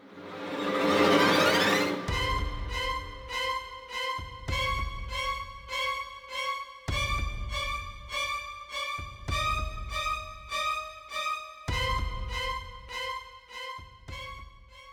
battle theme